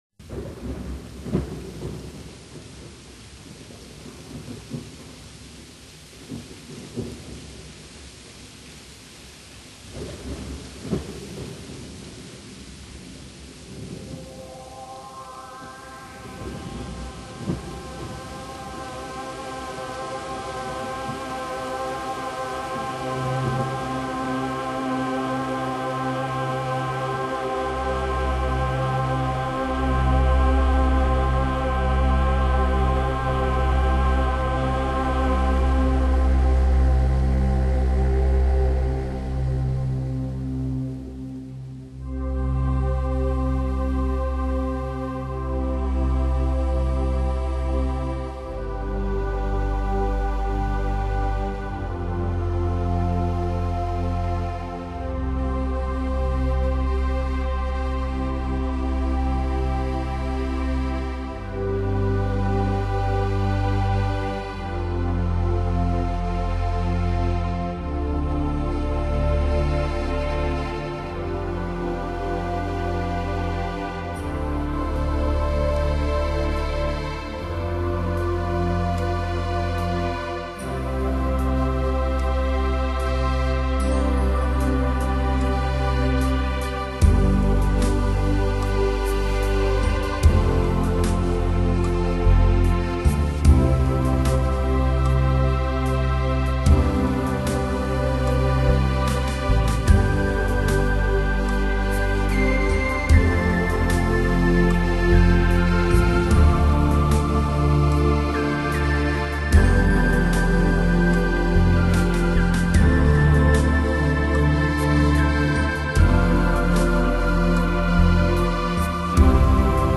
非洲最西端的遥远国度，饶富想像的神秘音乐。